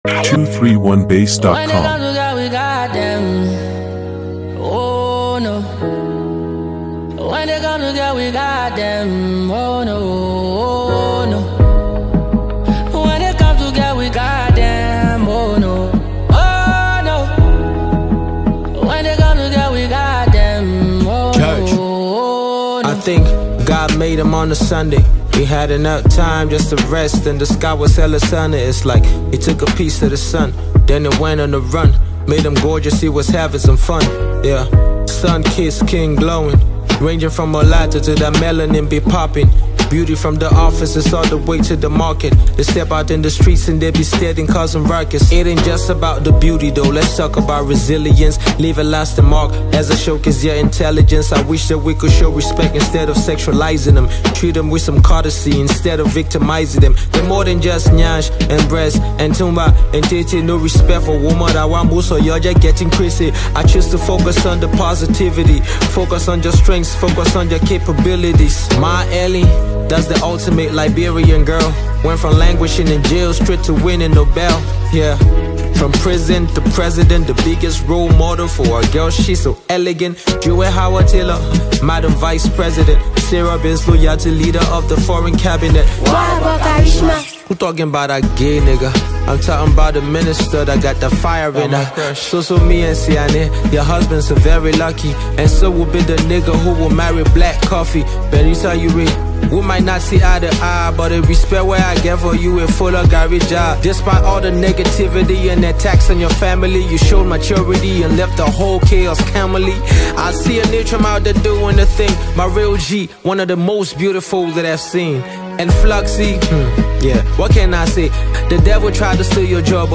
singer/songwriter
smooth flow
catchy vocals